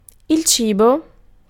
Ääntäminen
Tuntematon aksentti: IPA: /a.li.mɑ̃/